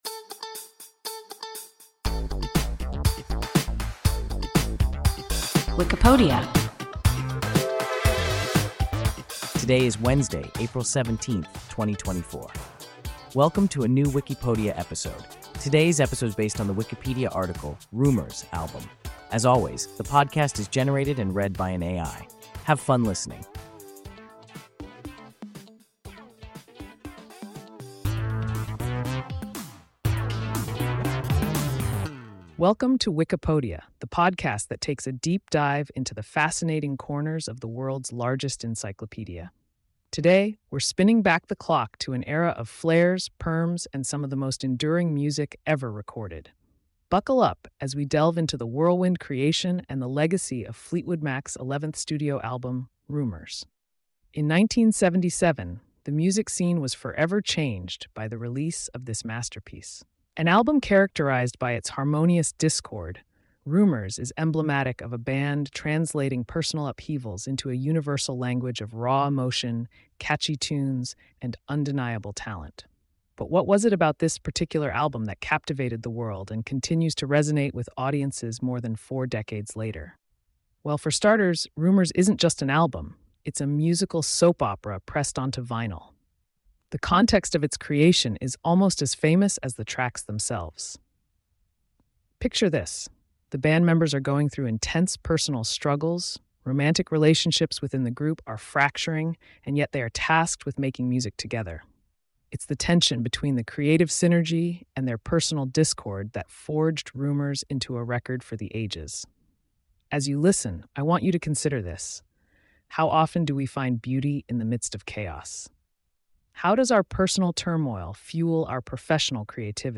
Rumours (album) – WIKIPODIA – ein KI Podcast